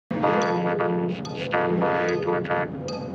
BSG Centurion - Arm weapons
BSG_Centurion_-_Arm_weapons.wav